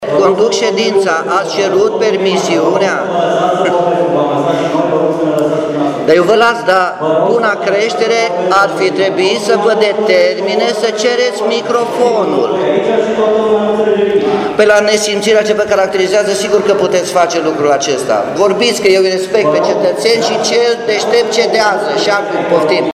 La dezbaterea pentru proiectul de buget, au participat mai puțin de 20 de cetățeni. Cu toate acestea, tensiunile nu au lipsit, primarul Nicolae Robu și consilierul Bogdan Herzog având un schimb dur de replici: